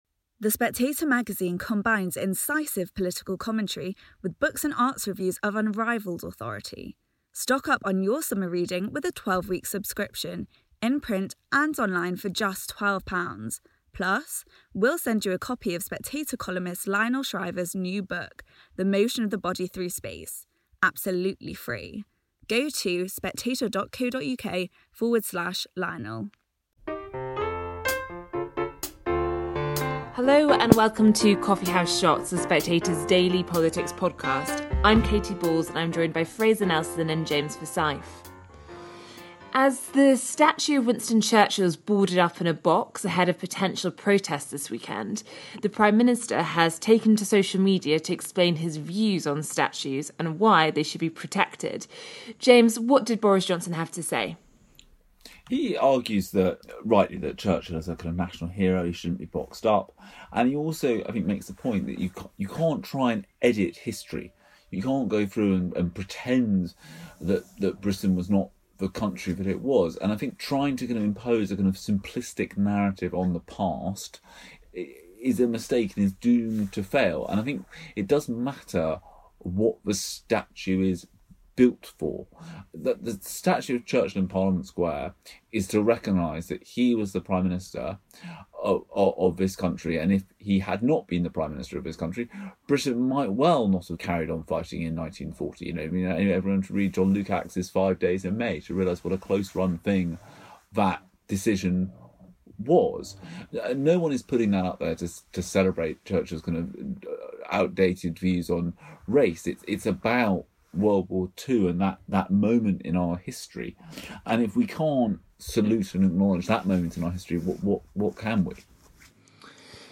Katy Balls talks to Fraser Nelson and James Forsyth about the looming Tory fightback against identity politics.